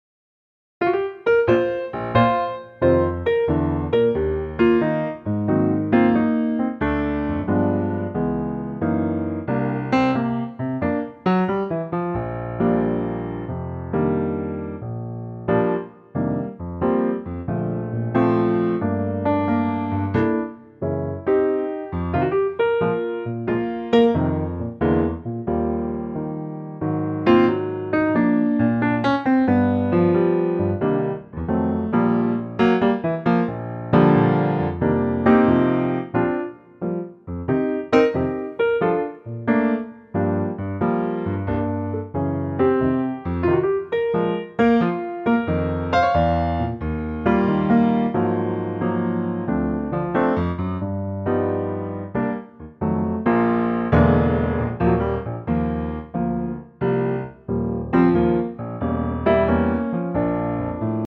key - Bb - vocal range - Bb to C